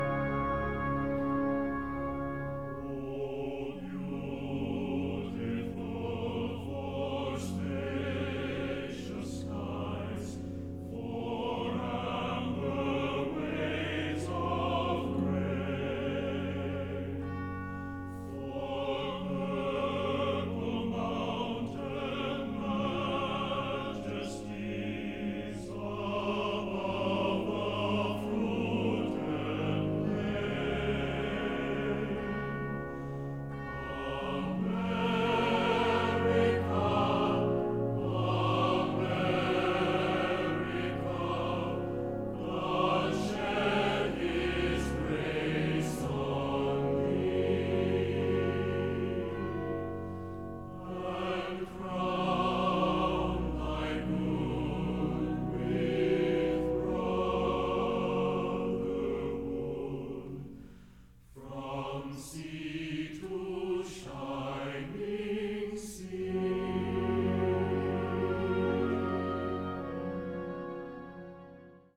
[Music clip: USAF Band & Singing Sergeants,
That was the United States Air Force Band & Singing Sergeants celebrating our lovely country.